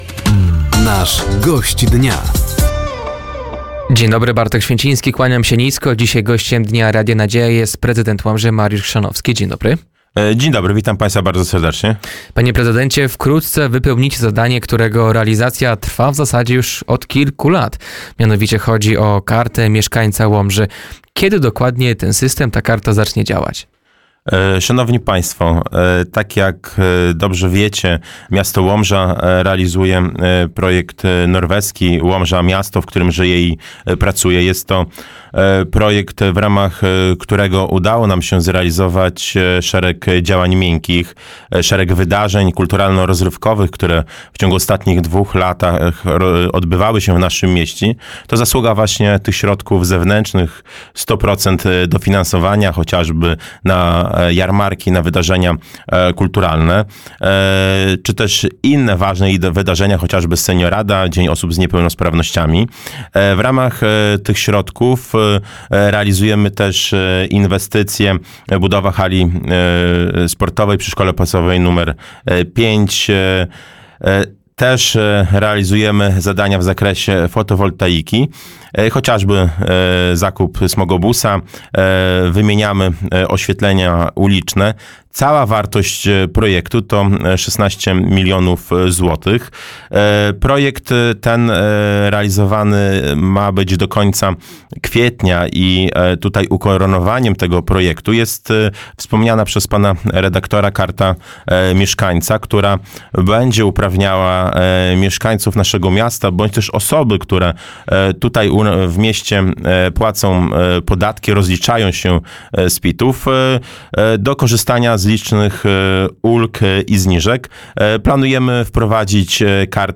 Gościem Dnia Radia Nadzieja był dziś prezydent Łomży, Mariusz Chrzanowski. Tematem rozmowy była Karta Mieszkańca Łomży, której realizacja będzie możliwa dzięki Funduszom Norweskim.